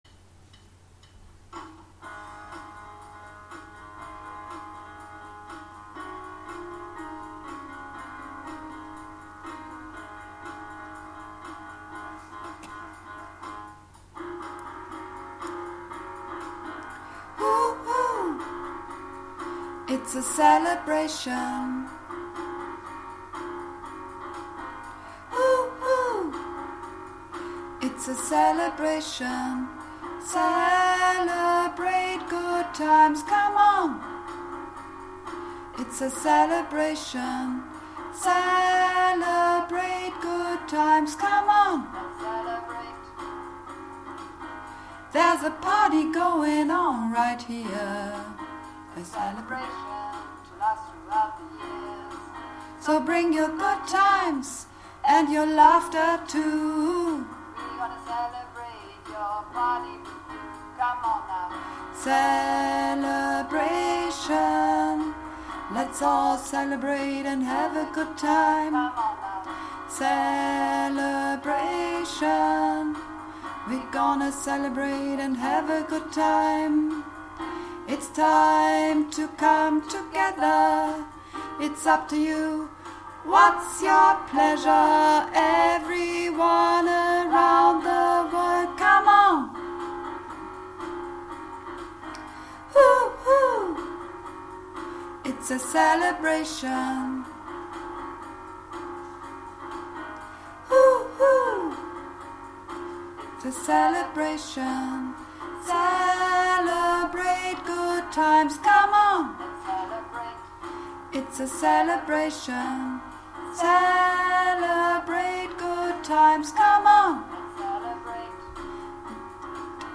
(Übungsaufnahmen)
Celebration - Alt
Celebration_-_Alt.mp3